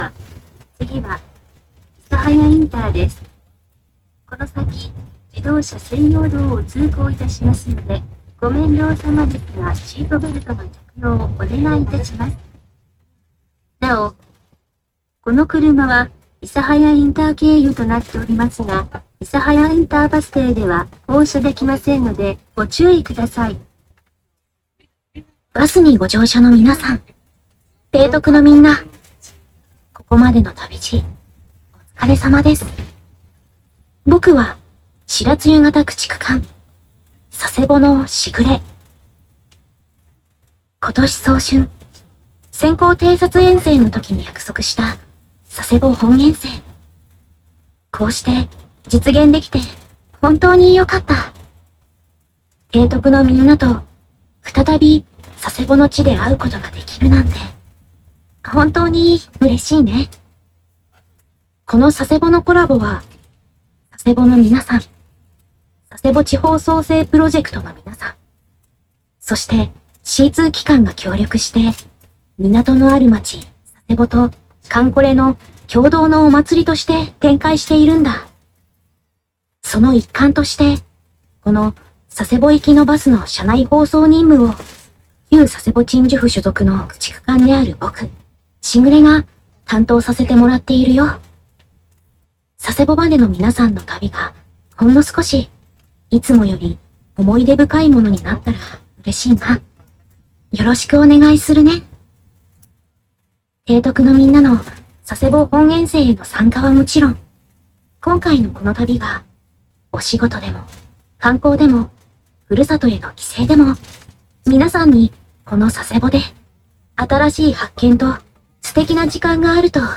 朋友剛錄的熱騰騰西肥彩繪巴士內時雨廣播錄音